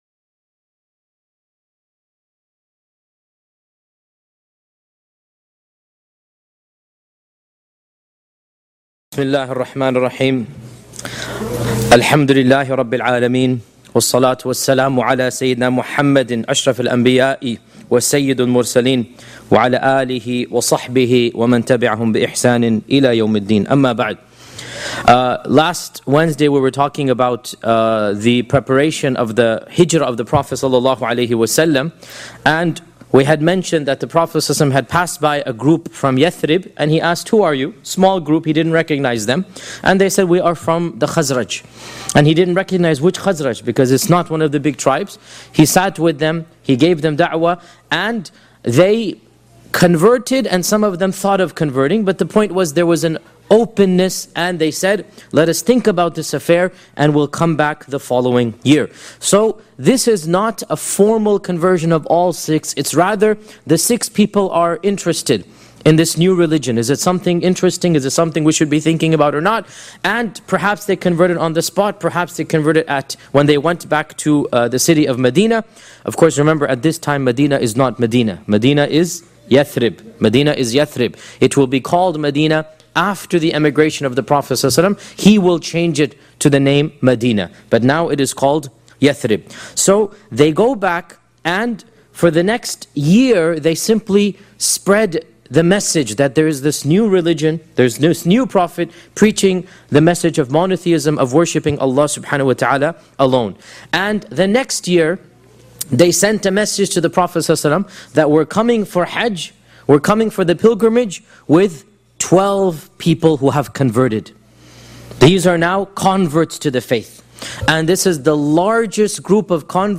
Shaykh Yasir Qadhi gives a detailed analysis of the life of Prophet Muhammed (peace be upon him) from the original sources.
This Seerah lecture covers the pivotal moment when Islam began to take root outside of Makkah — the first and second pledges of Aqabah.